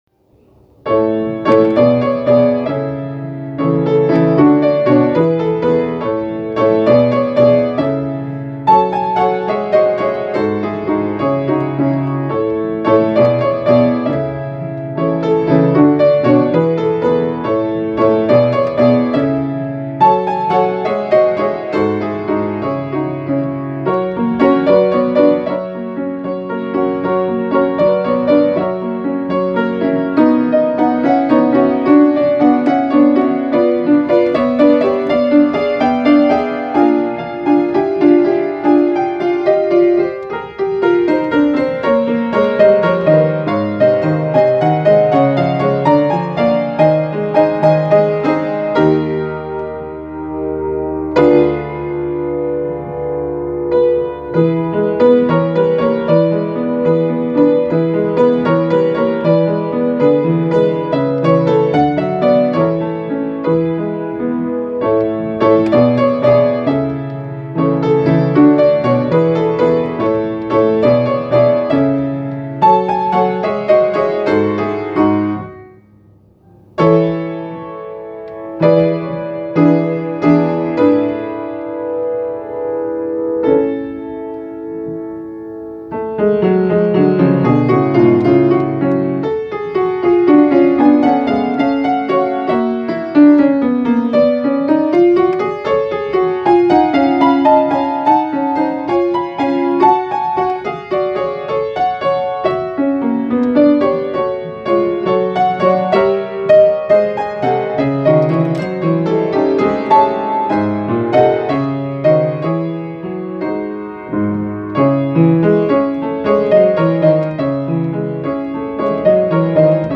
Intermezzo in A - Piano Music, Solo Keyboard - Young Composers Music Forum
This Intermezzo is intended to be the first of four little piano pieces I intend to finish. I write this as relaxation piece after finishing the very heavy String Sextet which would be published in the near future.